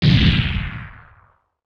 Power Laser Guns Demo
Plasm_gun06.wav